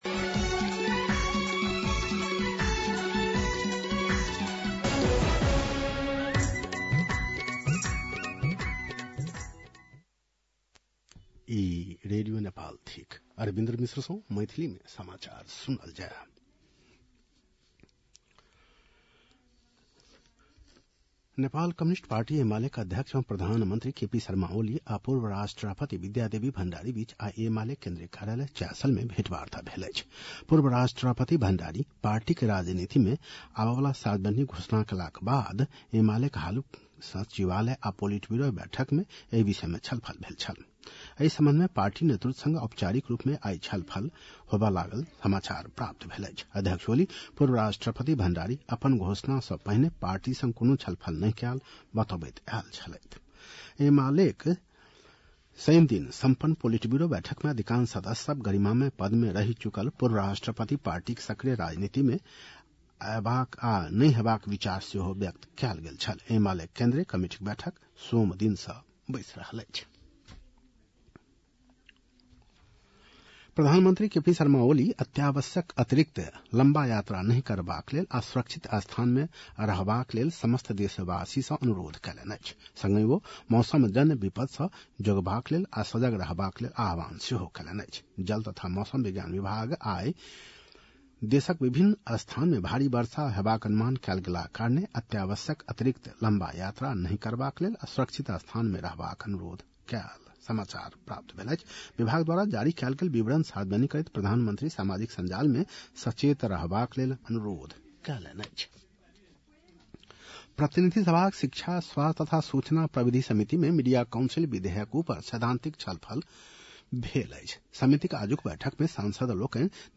मैथिली भाषामा समाचार : ४ साउन , २०८२